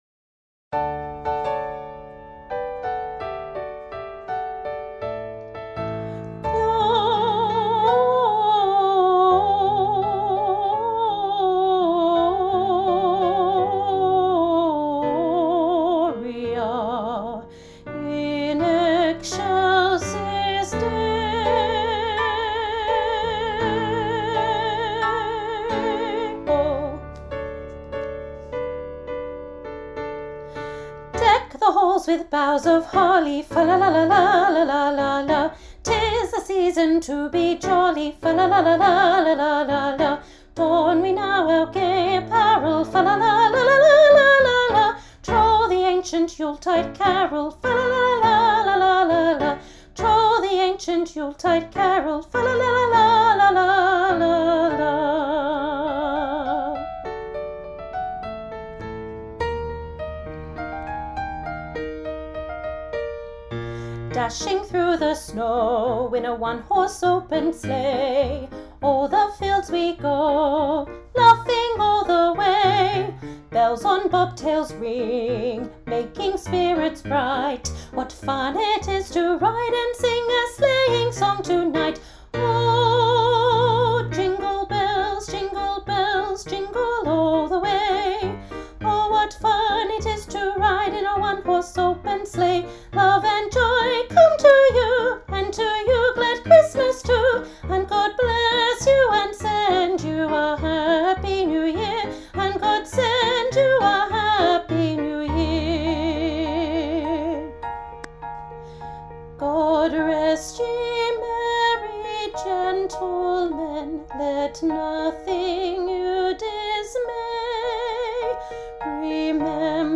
Junior Choir – Christmas Medley – National Boys Choir of Australia
Junior Choir – Christmas Medley